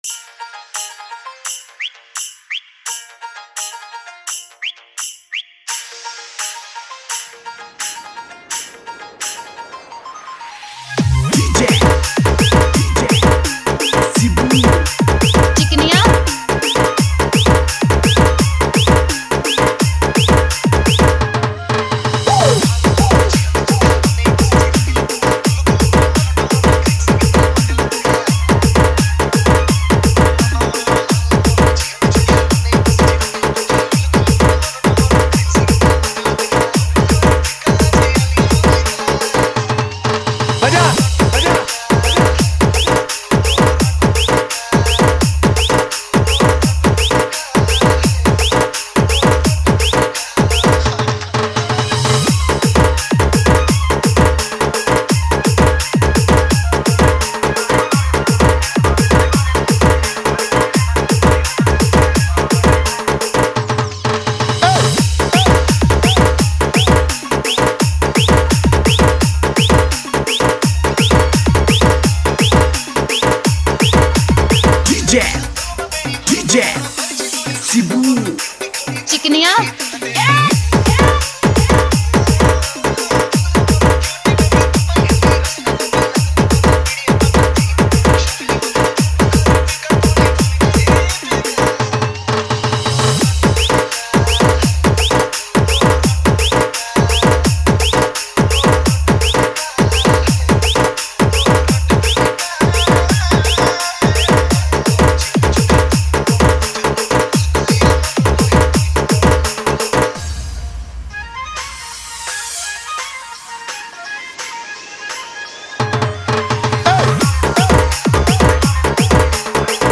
Category:  New Odia Dj Song 2021